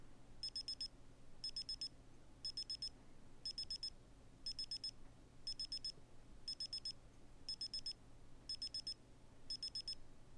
Nach Ablauf der Zeit ertönt 1 kurzer Piepston in normaler Lautstärke (OnePiep).
Klangdatei Meditationstimer Memo OnePiep, 1 kurzer Piepser
• Lautes Tonsignal auf ein kurzen Piepston modifiziert